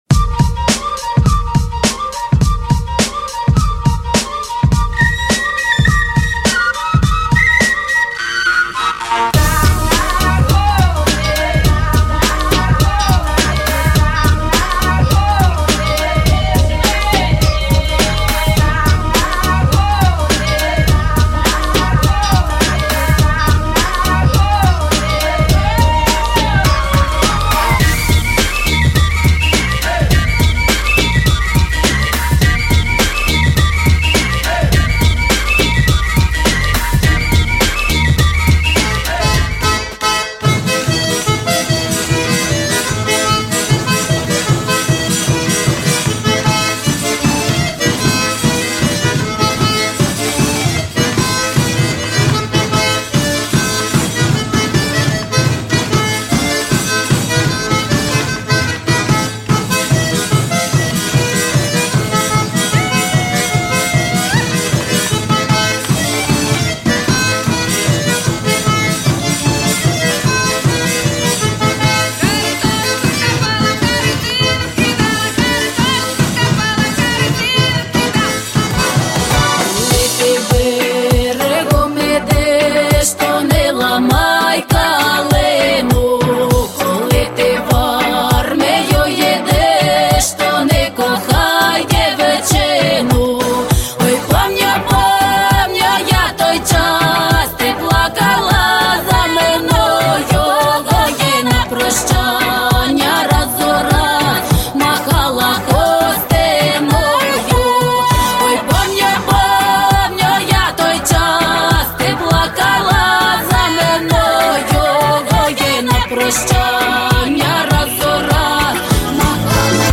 Украинские народные песни в современной обработке: